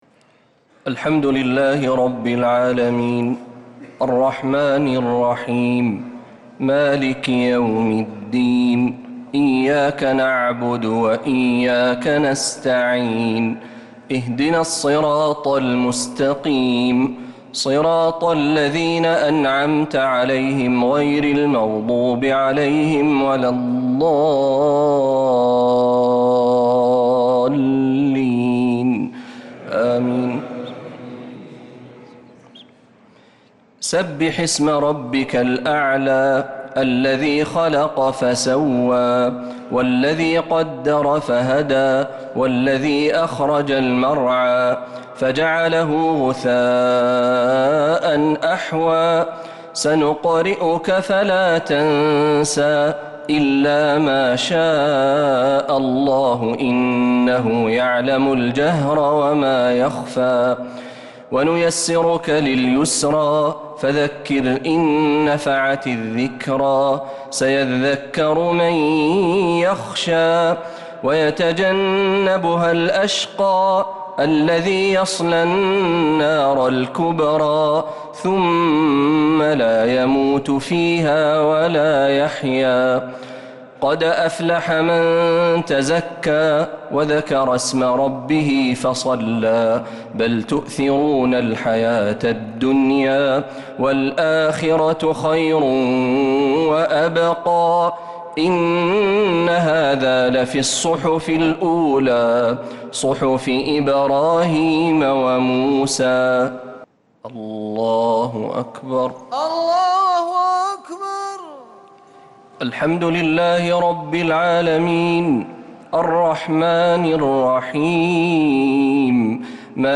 صلاة الشفع و الوتر ليلة 3 رمضان 1446هـ | Witr 3rd night Ramadan 1446H > تراويح الحرم النبوي عام 1446 🕌 > التراويح - تلاوات الحرمين